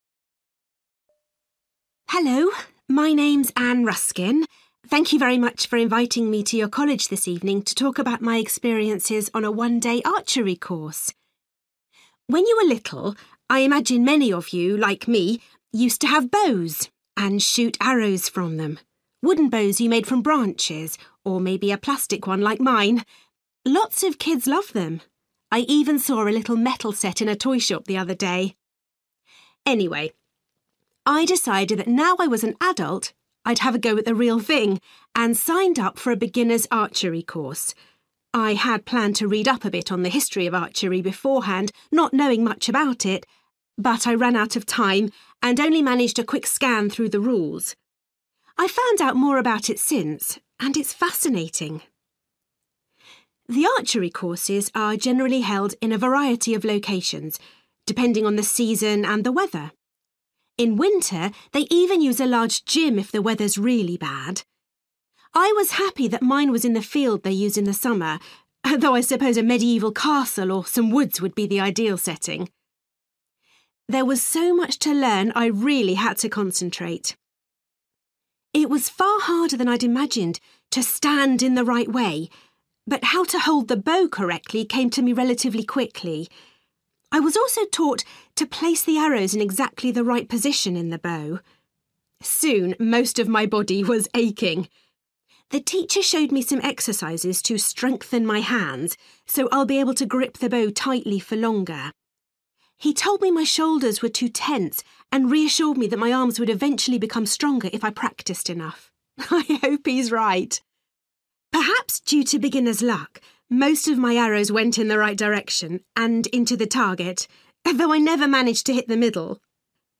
You will hear a woman